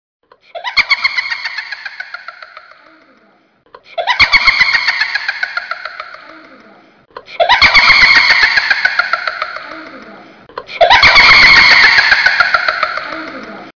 女人诡异笑声音效_人物音效音效配乐_免费素材下载_提案神器
女人诡异笑声音效免费音频素材下载